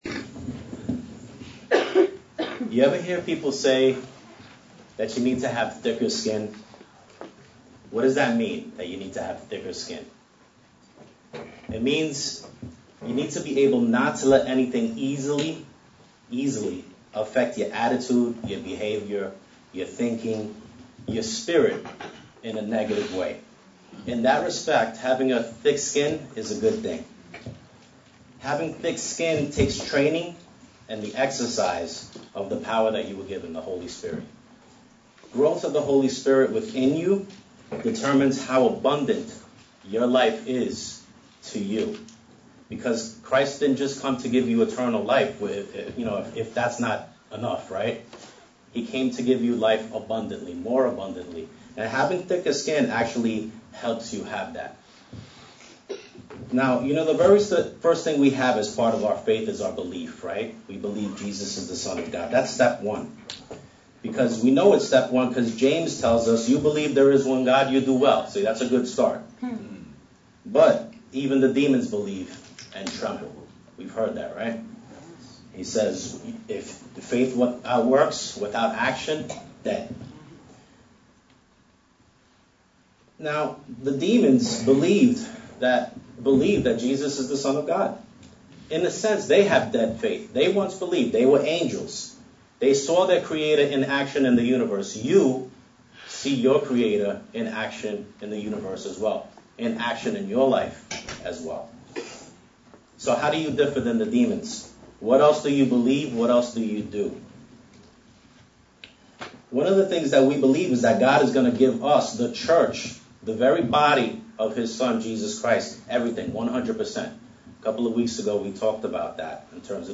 Split sermon on using the epistles of Peter, Paul, and John to understand the levels of spiritual growth and to train yourself to grow 'thick skin' for successful spiritual warfare.